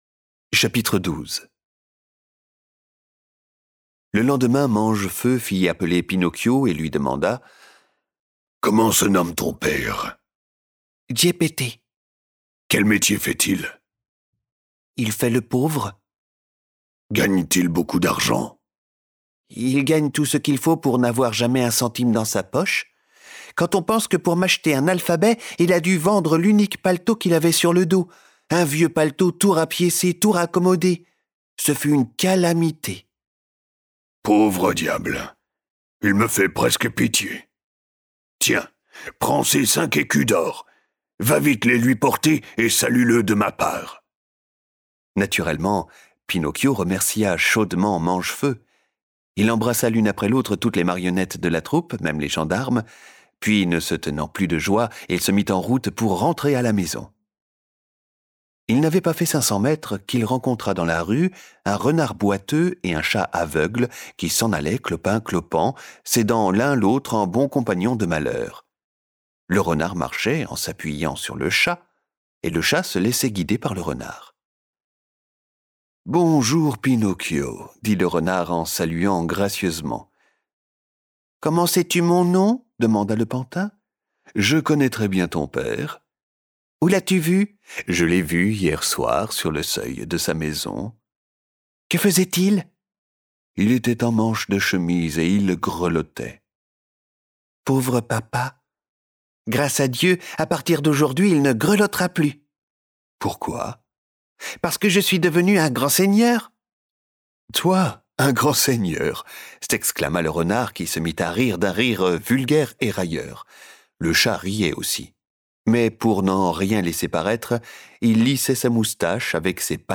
Envie de découvrir de la littérature audio en famille ?